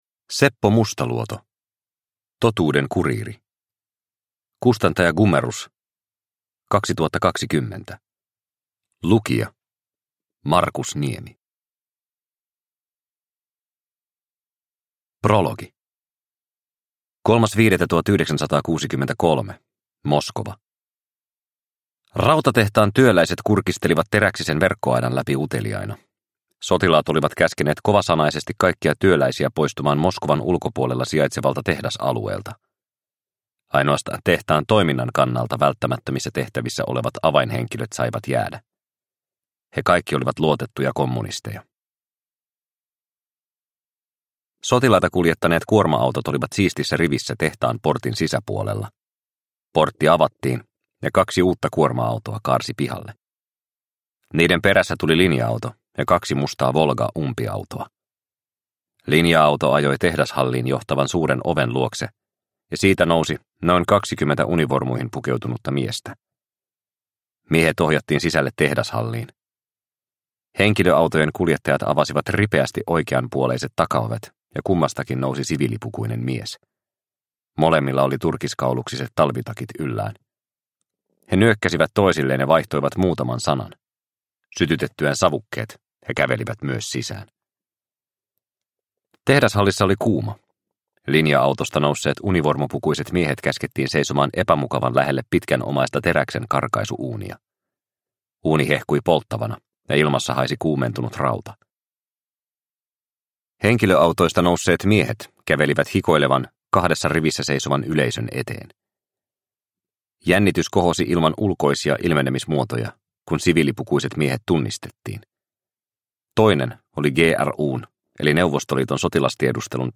Totuuden kuriiri – Ljudbok – Laddas ner